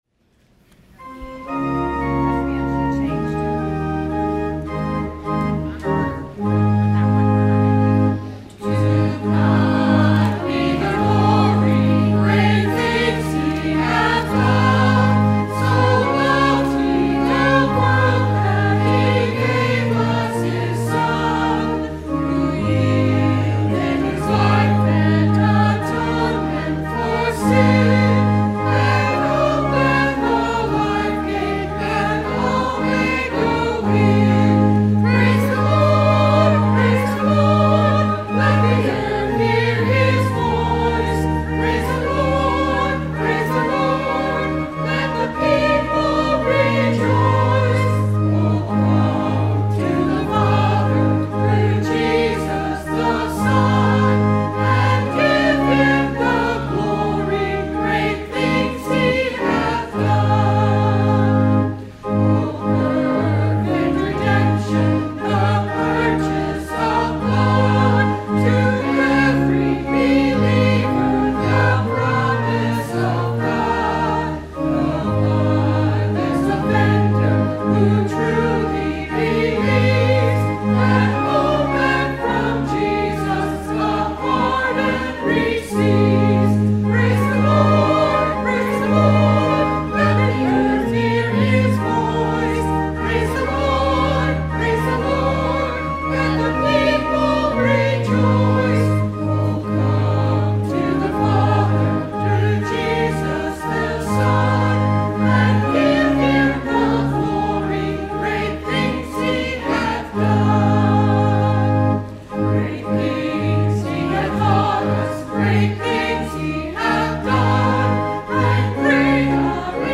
Sung by the Church and Choir.